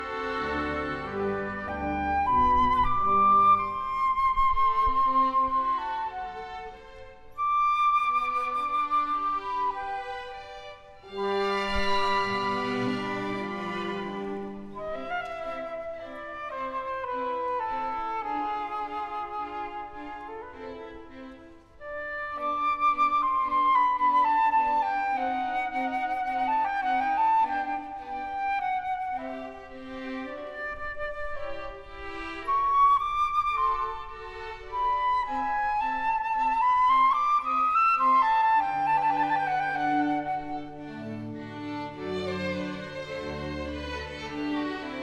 Совершенно непонтятная наводка(?) в левом канале на главной стереопаре. Ощущение, что фантома не хватает, но на линии и по питанию проблемы исключены (почти).
Брак носит разовый характер за полуторачасовую запись, больше такое не повторялось (тьфу х3 раза).